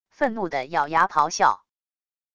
愤怒的咬牙咆孝wav音频